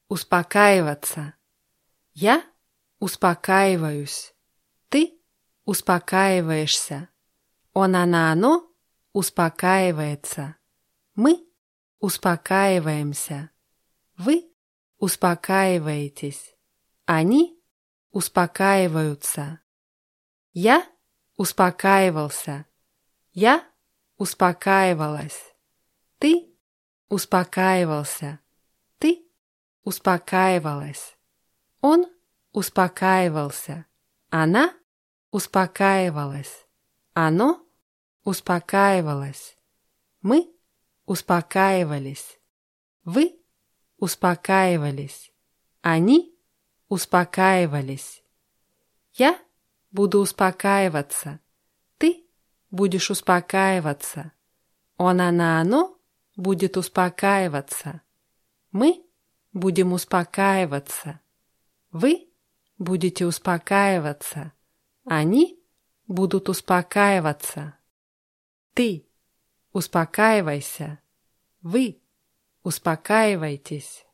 успокаиваться [ußpakáiwatsa]